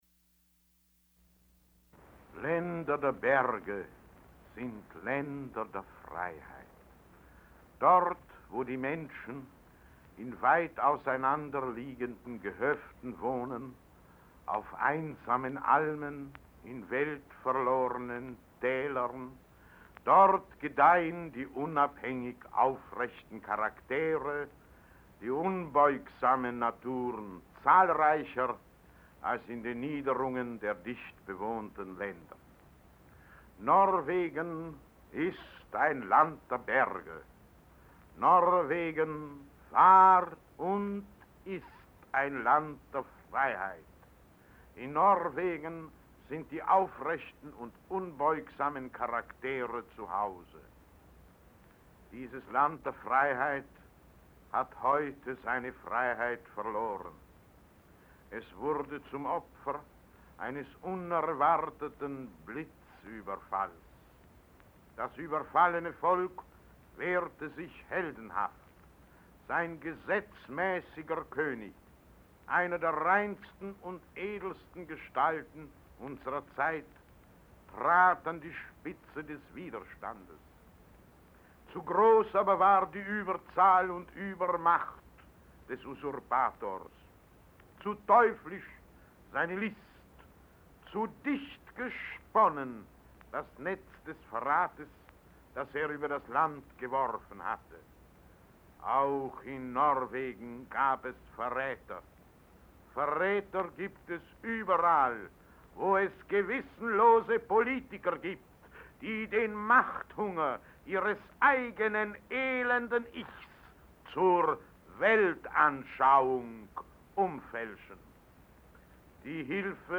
Franz Werfel reads his poem celebrating Norway as a land of mountains and freedom
Taped from Deutsches-Rundfunk-Archiv.